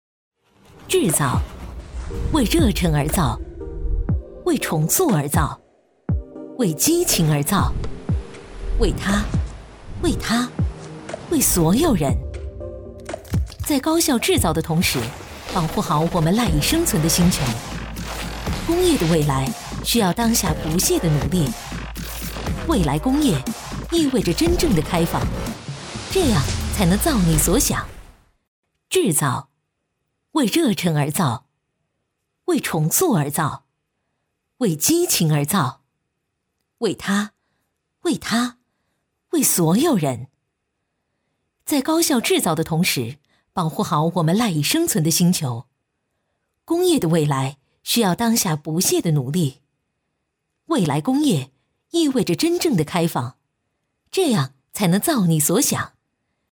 科技感配音【海豚配音】
女29-科技感 制造
女29-科技感 制造.mp3